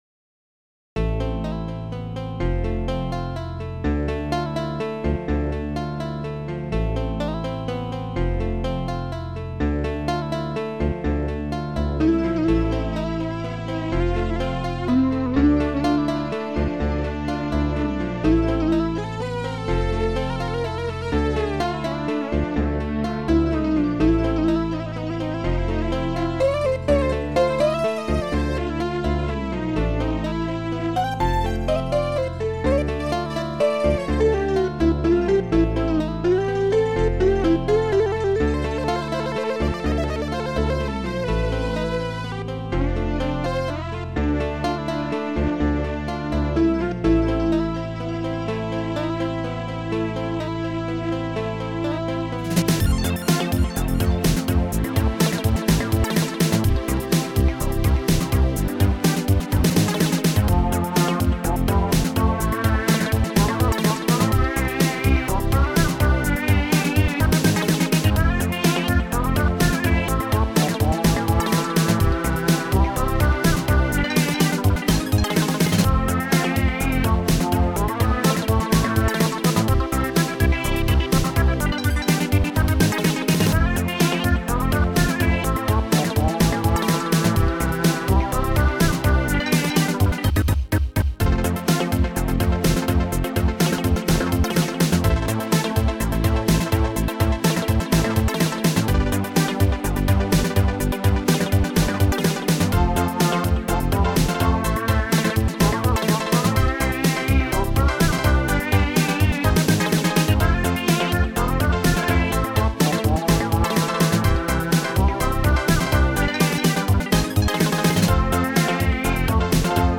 Protracker and family
st-00:acousticguitar
st-00:slidebass
ST-23:d20brushsnare
mixed:Bassdrum-hihat
ST-19:cdShaker-2